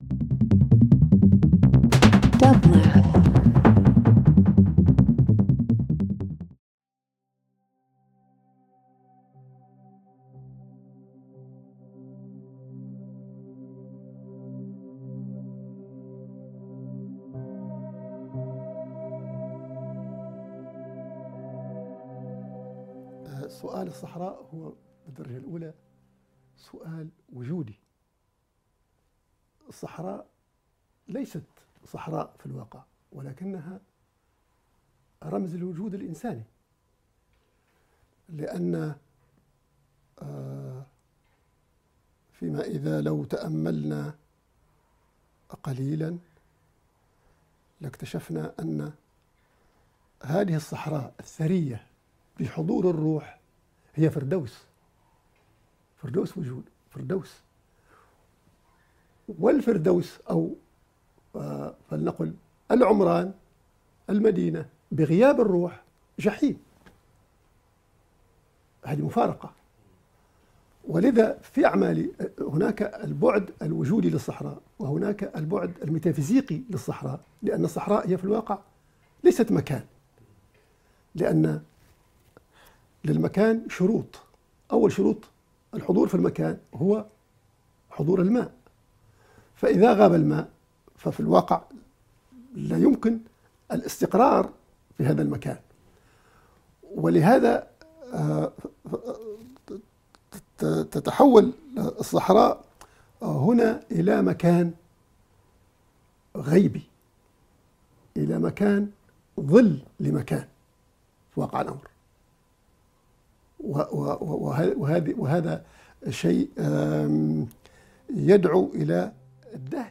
Experimental Instrumental International Soundtracks